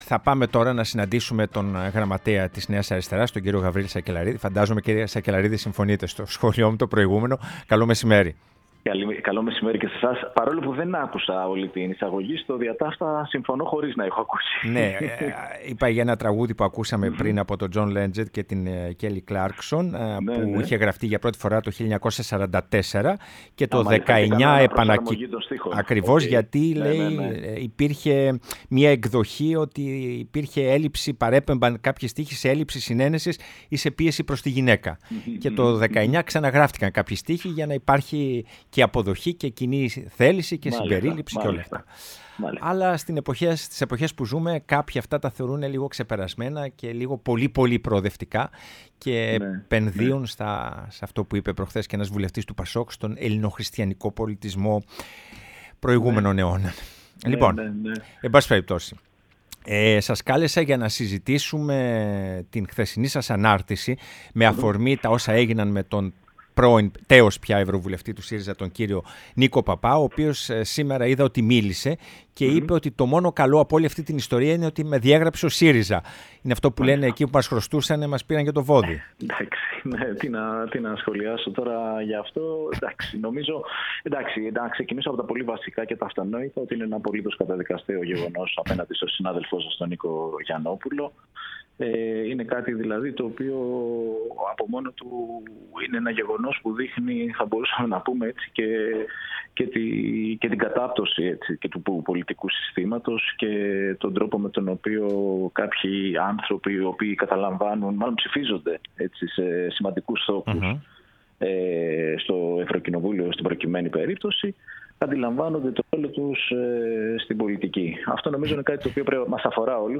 -Ο Γραμματέας της Νέας Αριστεράς Γαβριήλ Σακελλαρίδης, μίλησε στην εκπομπή “Ναι μεν αλλά”